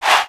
Vox
SouthSide Chant (36)(1).wav